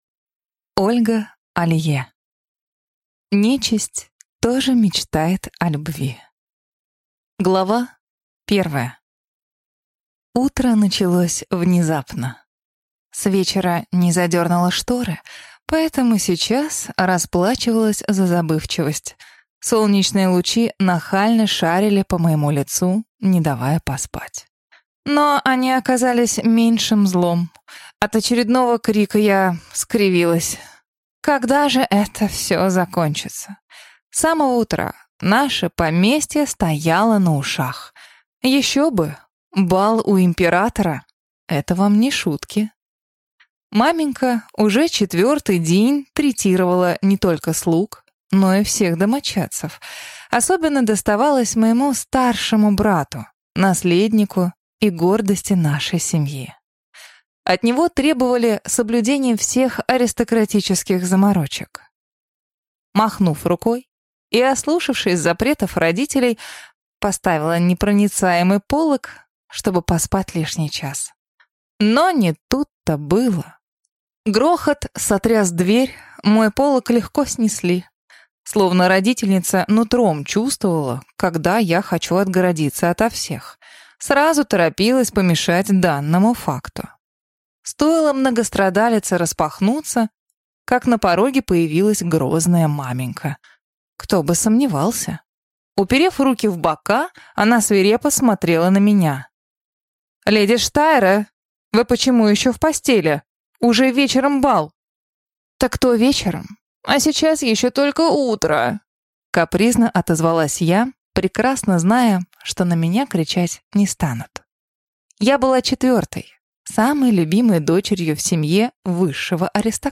Аудиокнига Нечисть тоже мечтает о любви | Библиотека аудиокниг